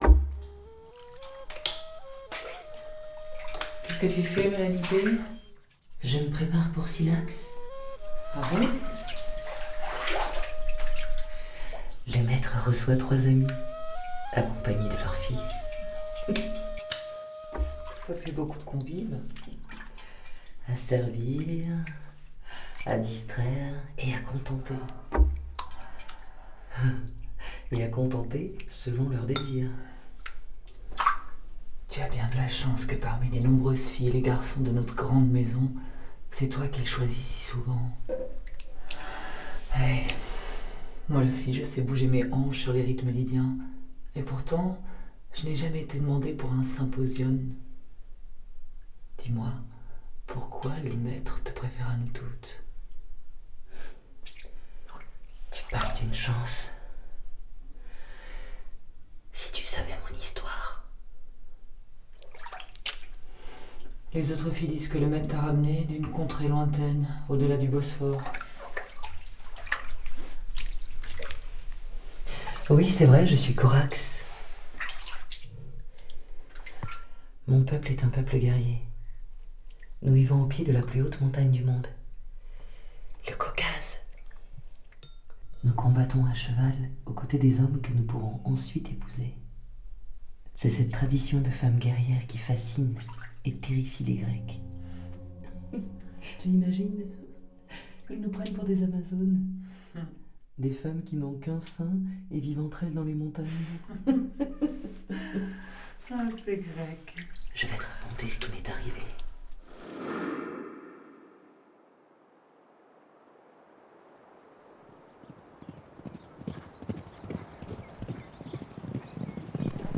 Pour une meilleure écoute en son stéréo, nous vous invitons à utiliser un casque.